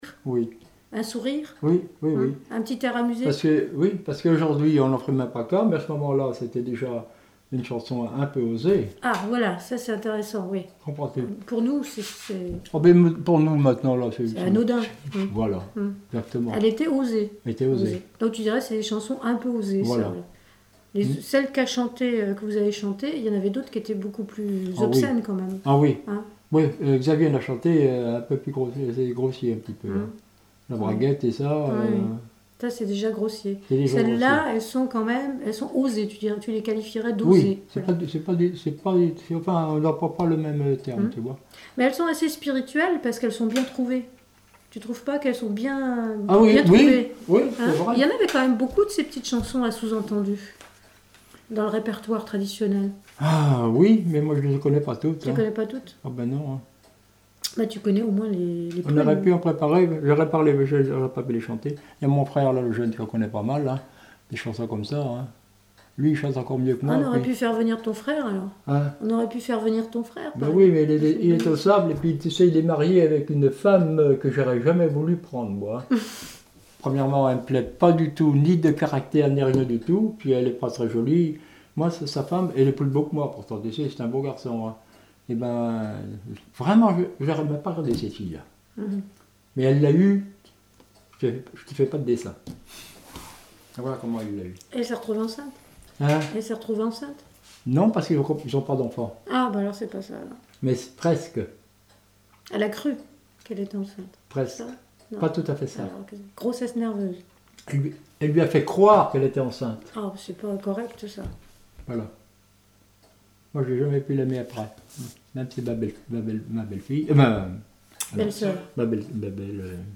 chanteur(s), chant, chanson, chansonnette
témoignages sur la chanson de tradition orale
Catégorie Témoignage